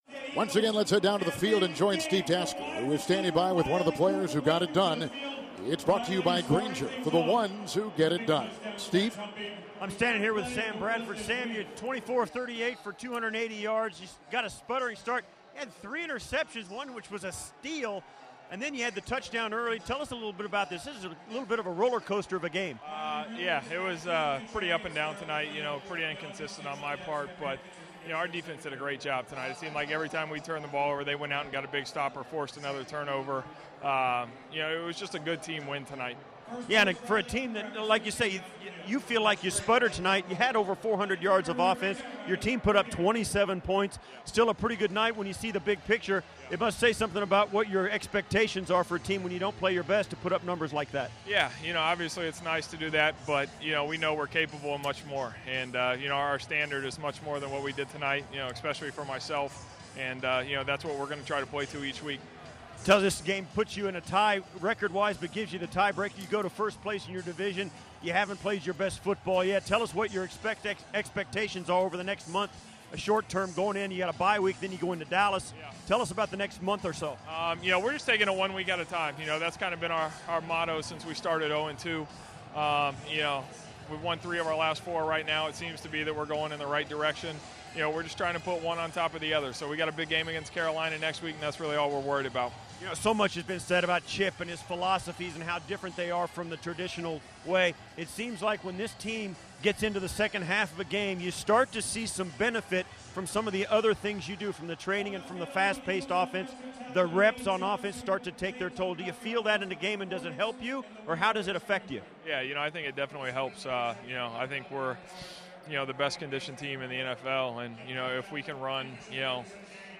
Sam Bradford Postgame Intv 10.19.15